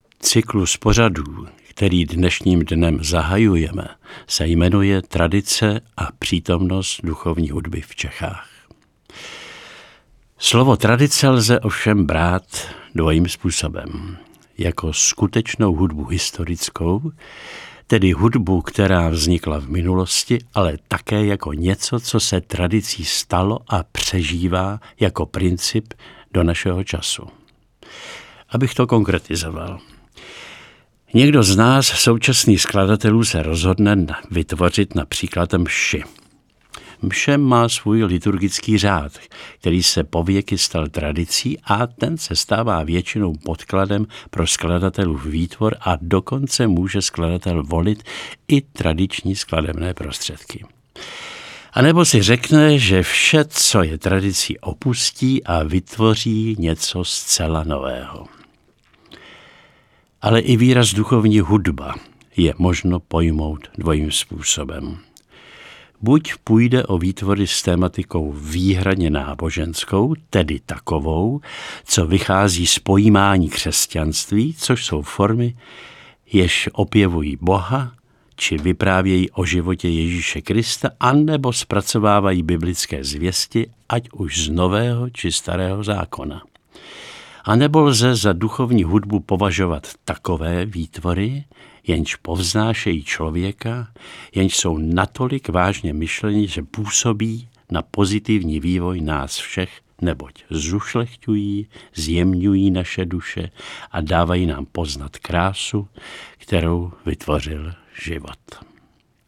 "Začínáme nový občanský rok a svěřujeme se do rukou Božích i pod ochranu Matky Boží, Panny Marie," řekl ve svém novoročním pozdravu posluchačům Radia Proglas plzeňský biskup František Radkovský.